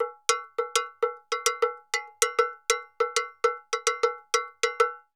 Campana_Salsa 100_4.wav